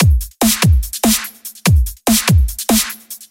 描述：这是一个简单而有力的踢鼓循环，它是在我的模拟模块化合成器上用正弦波合成的。
标签： 145 bpm Trance Loops Drum Loops 570.30 KB wav Key : Unknown
声道立体声